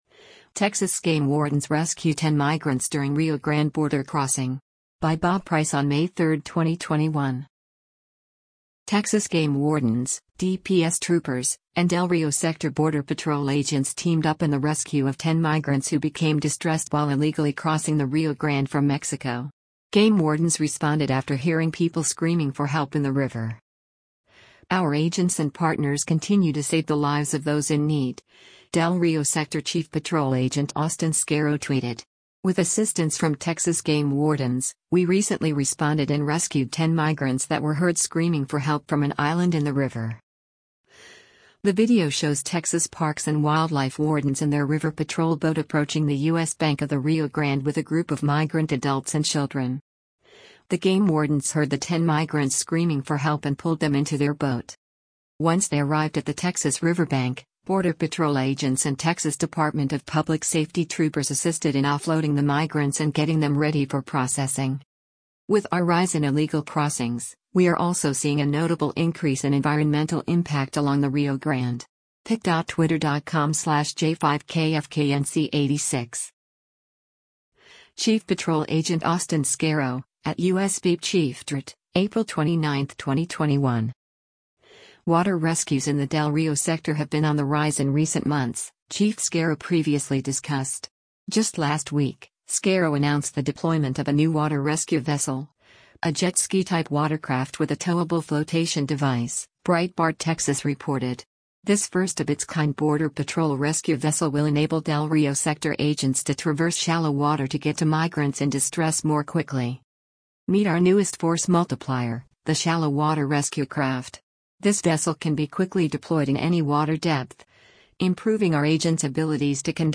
The video shows Texas Parks and Wildlife wardens in their river patrol boat approaching the U.S. bank of the Rio Grande with a group of migrant adults and children.